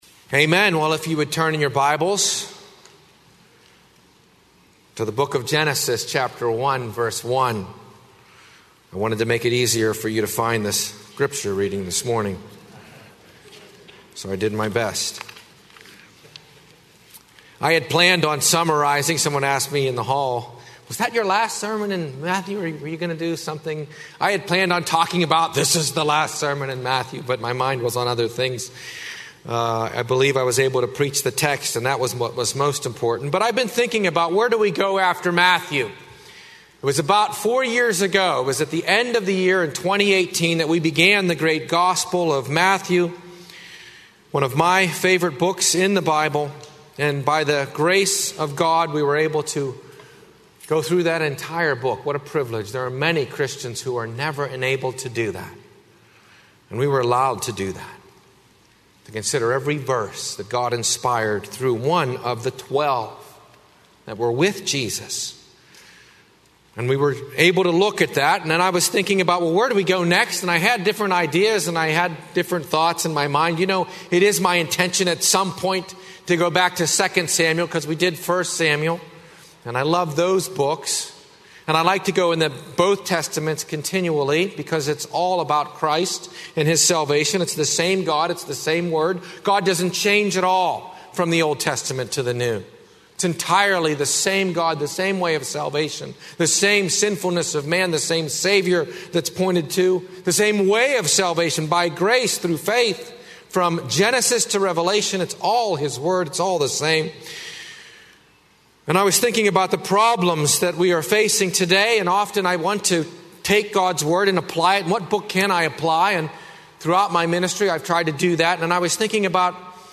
00:00 Download Copy link Sermon Text Genesis 1:1